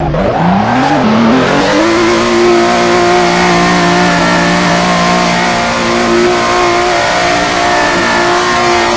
rev.wav